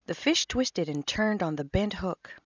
FSPEECH3.WAV    Speech signal reconstructed from modified envelopes:
envelopes for channels above 1500Hz sampled at 1.2
fspeech3.wav